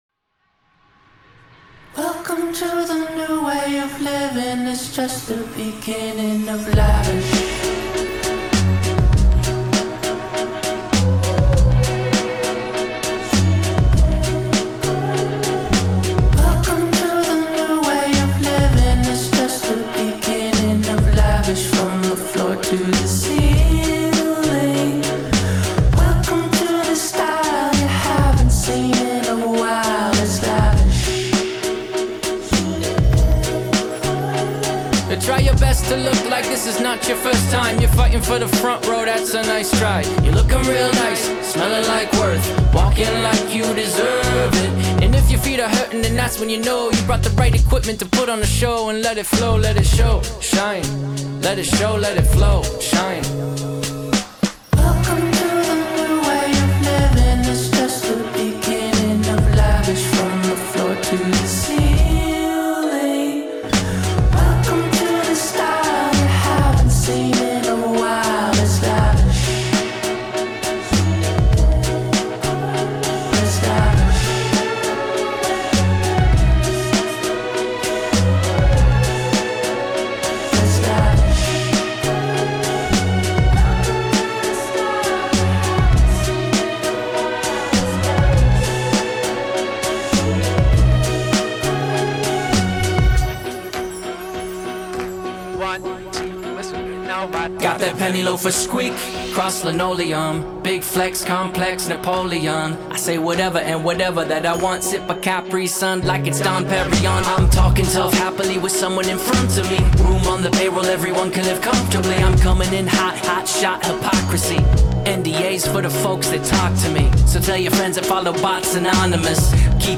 • Жанр: Alternative